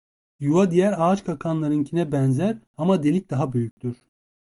Pronunciado como (IPA) /di(j)æɾ/